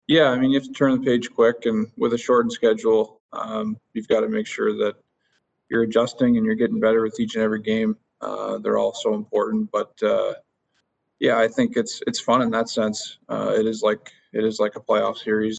Crosby says the Penguins can’t dwell on losses in this season, with the schedule being so short and every game so important to their playoff chances.